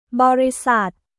บริษัท　 ボーリサット